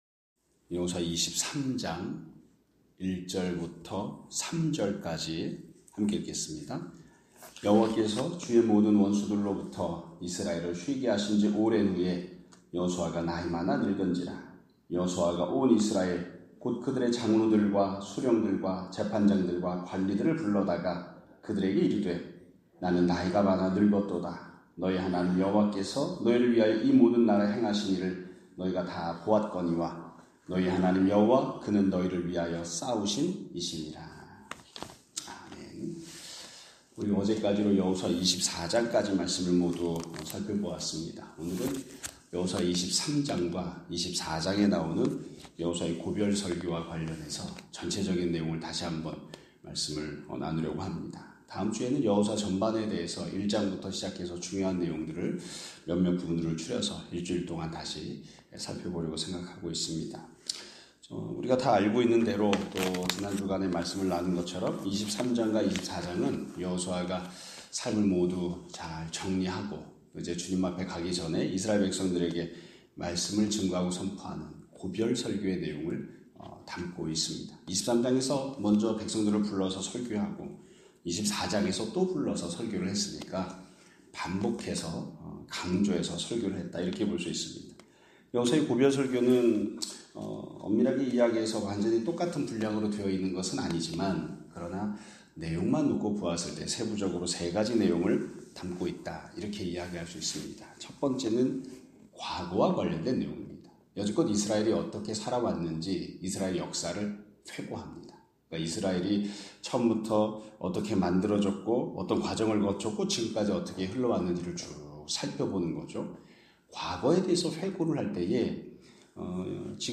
2025년 3월 7일(금 요일) <아침예배> 설교입니다.